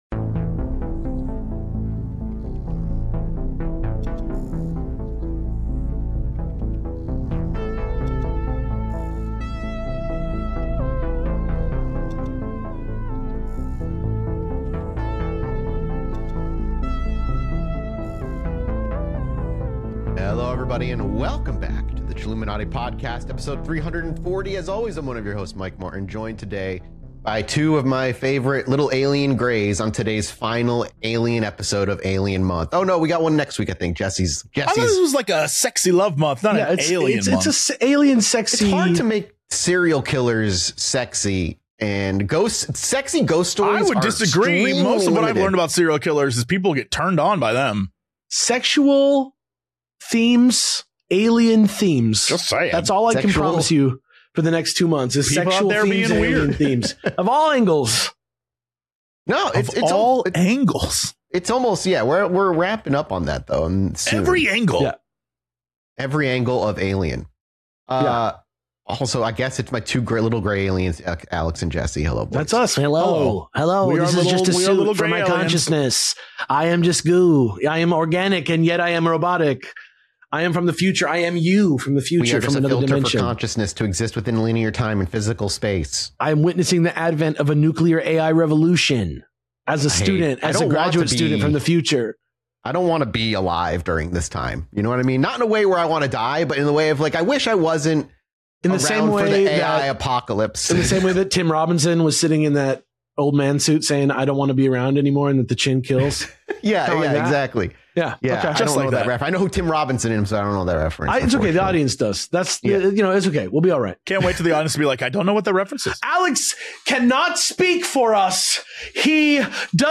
CHILLUMINATI is a weekly comedy podcast